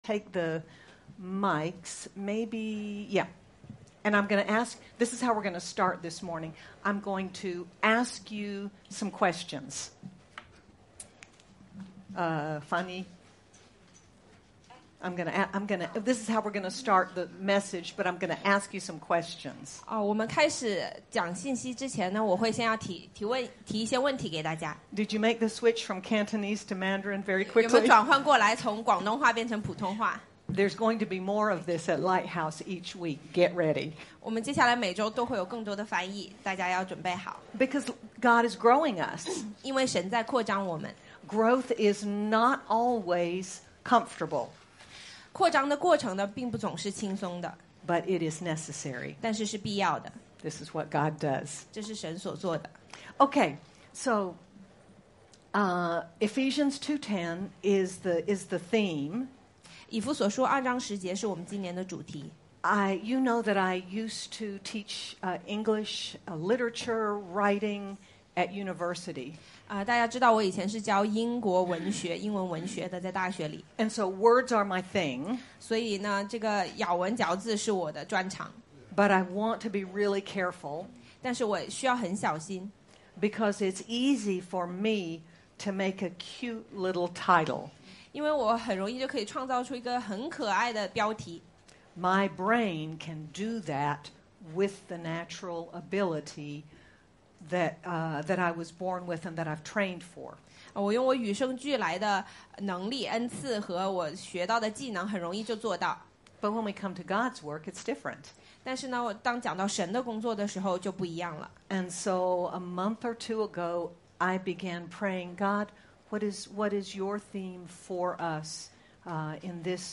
Knowing us so fully as He does, He has made us for good works. Sermon by